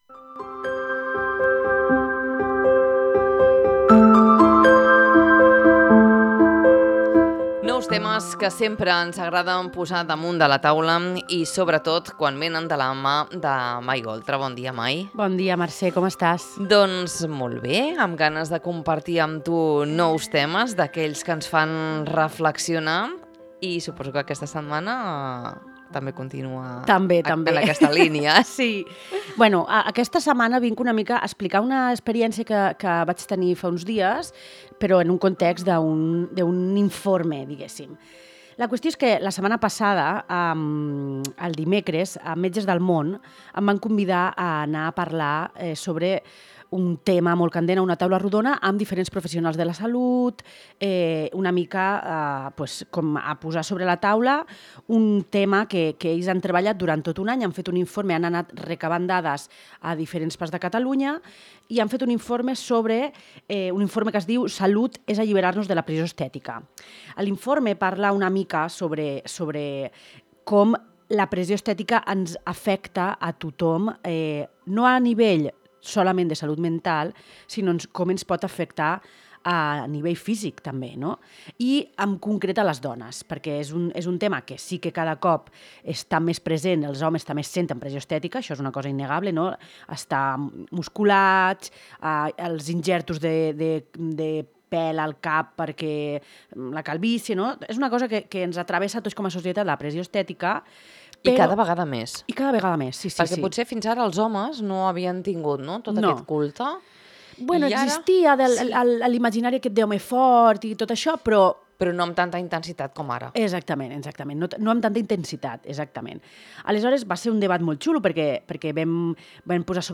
Una conversa per reflexionar, desmuntar mites i posar paraules a allò que sovint ens costa explicar.